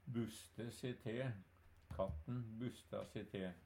DIALEKTORD PÅ NORMERT NORSK buste se te bli sinna/brygge opp til dårleg vêr Infinitiv Presens Preteritum Perfektum Eksempel på bruk Katten busta se te.